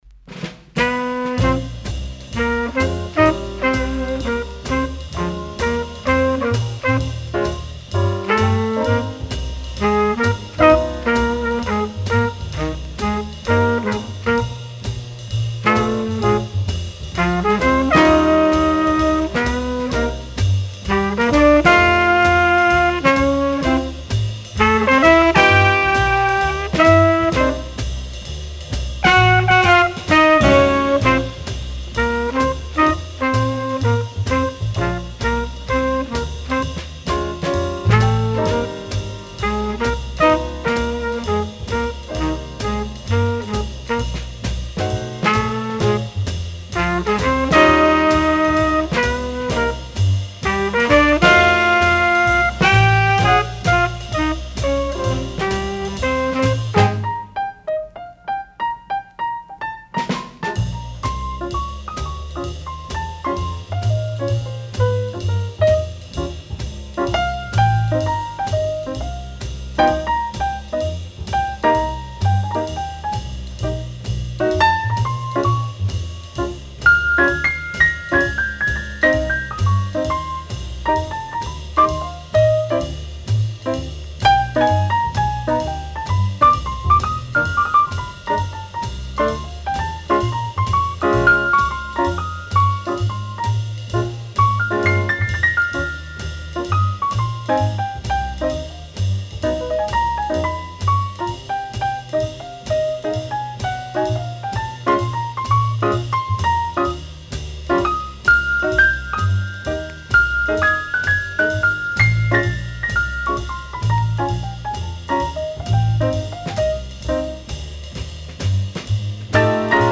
Recorded October 6, 1961 in New York City